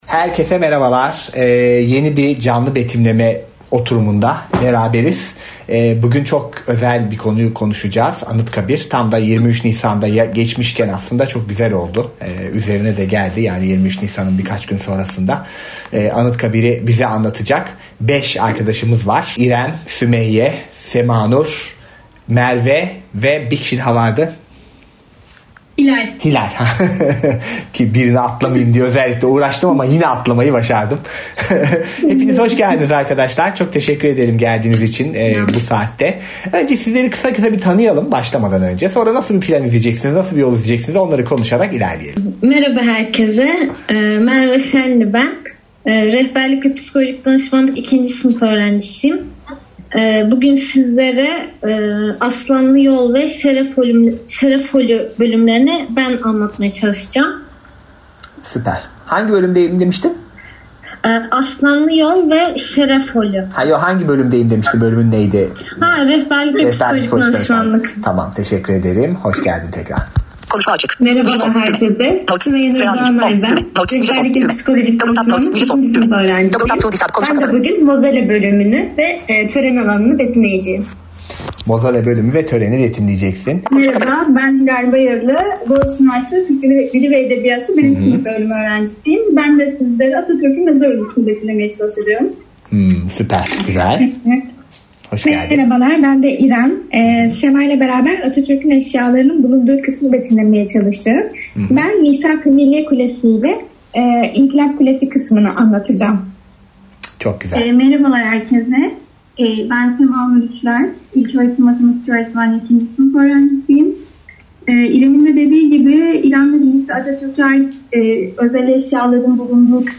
Anıtkabir Betimlemesi 27.04.2020 | Dünyaya Seslen
Canlı Betimlemeler